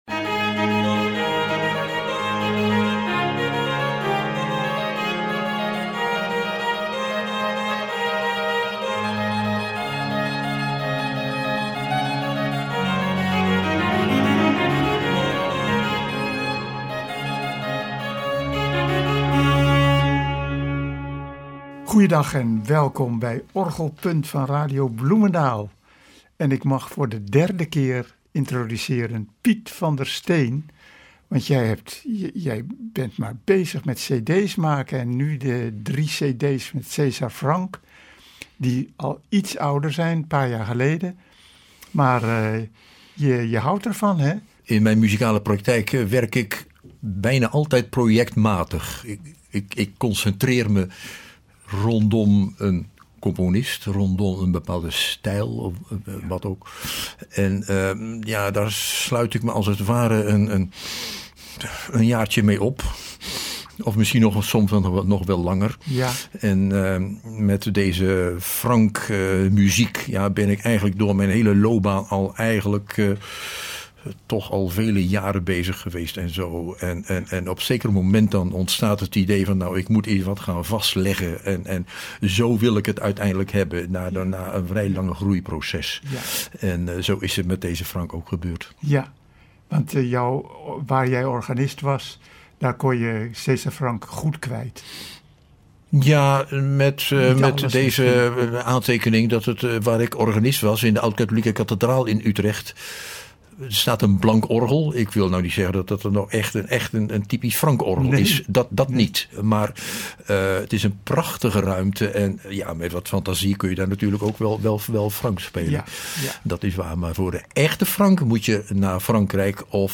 Het zijn dan ook niet minder dan 3 cd’s vol muziek, waaronder ook een alternatim Magnificat, waarbij dus ook gezongen wordt.